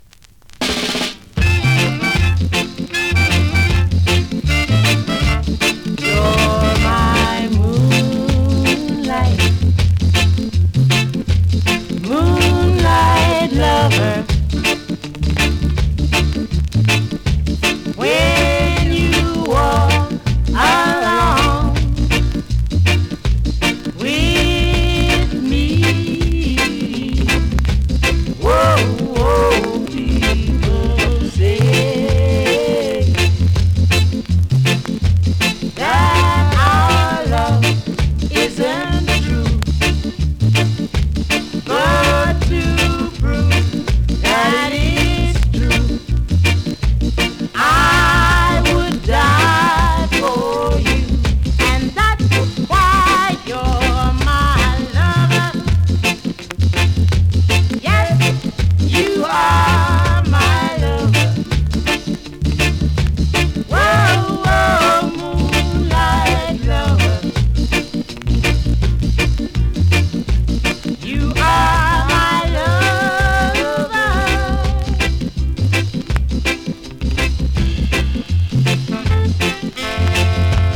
NEW IN!SKA〜REGGAE
スリキズ、ノイズそこそこありますが